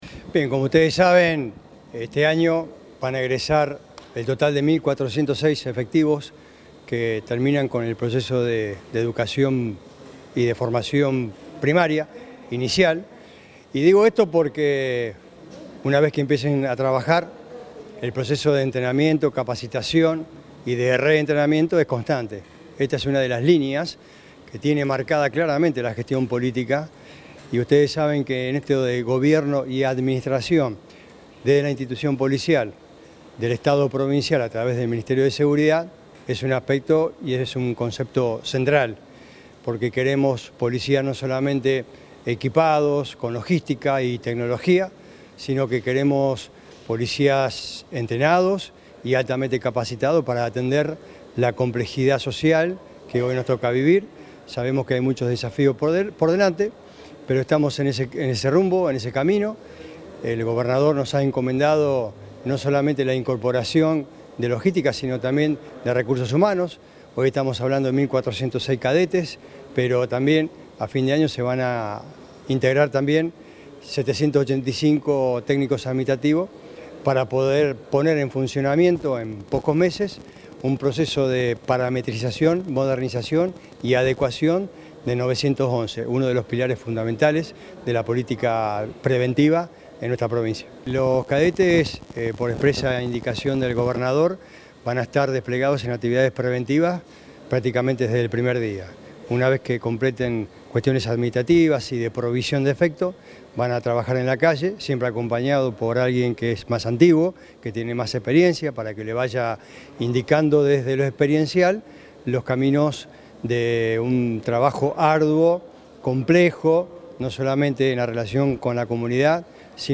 El gobierno provincial, a través del Ministerio de Seguridad, realizó este lunes el acto de egreso de 741 cadetes que ingresan a la Policía provincial como suboficiales de Policía del Escalafón General - Subescalafón Seguridad.
Secretario de Seguridad Pública, Claudio Brilloni.